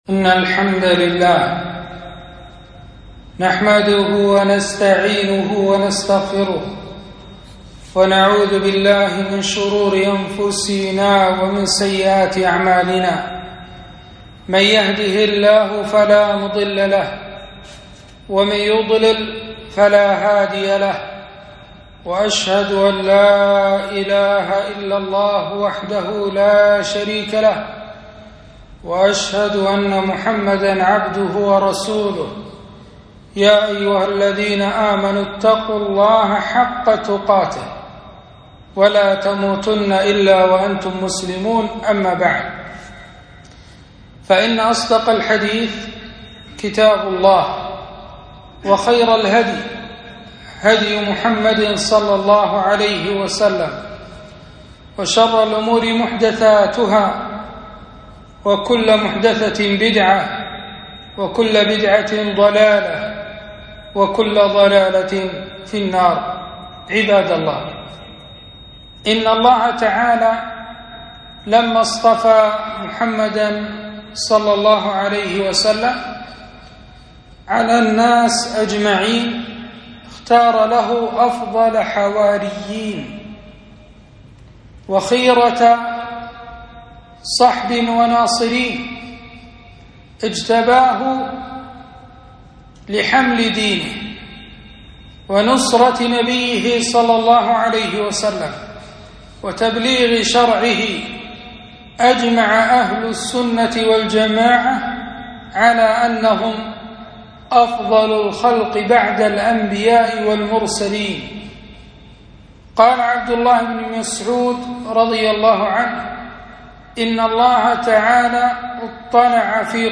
خطبة - فضل الصحابة ومكانتهم في الإسلام